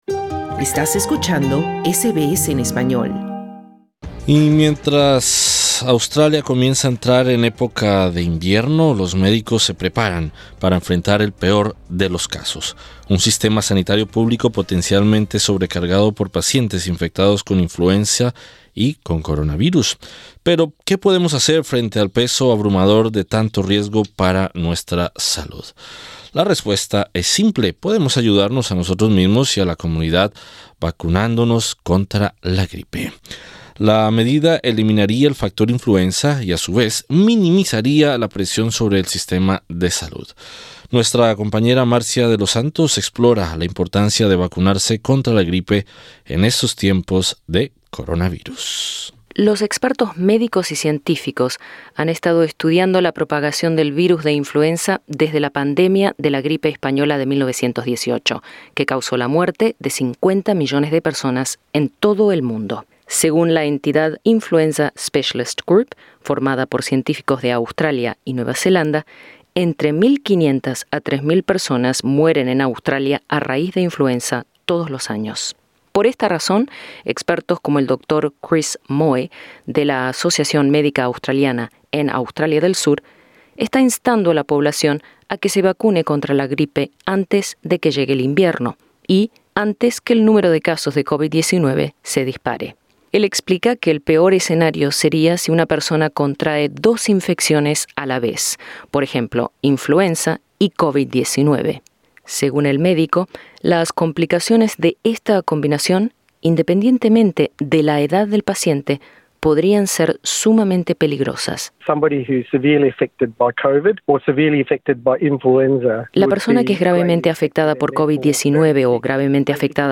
El Gobierno australiano está instando a la población a vacunarse contra la gripe lo antes posible, para evitar complicaciones graves de salud en caso de contraer dos infecciones a la vez; la influenza estacional y COVID-19. Escucha nuestro informe.